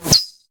mh_blade_snick1.ogg